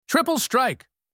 triple_strike.wav